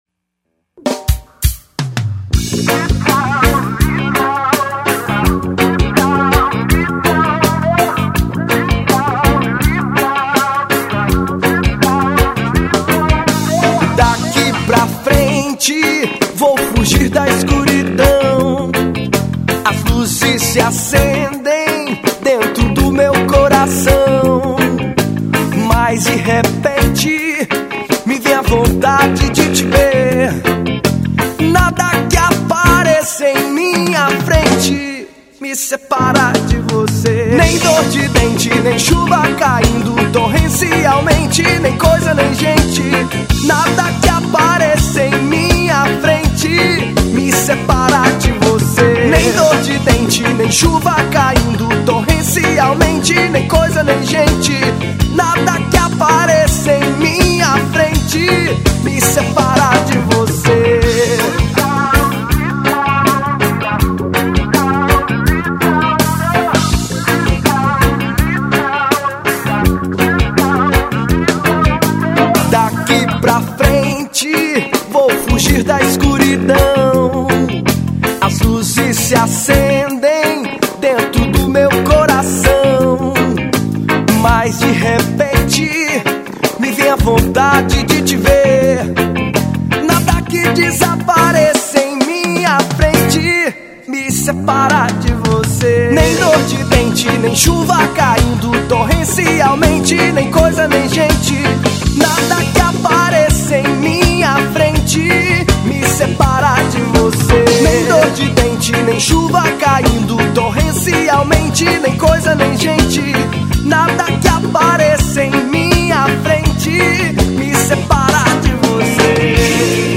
1992   02:41:00   Faixa:     Reggae